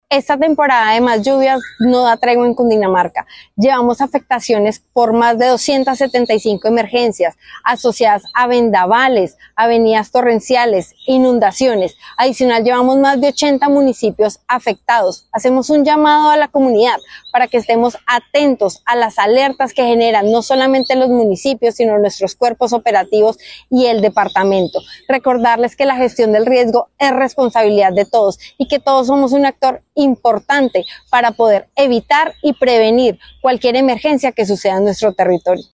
La directora de la Unidad de Gestión del Riesgo de Cundinamarca, Natalia Gómez, aseguró que siguen activos los planes de atención y pidió a la ciudadanía no bajar la guardia.